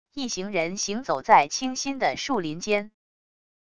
一行人行走在清新的树林间wav音频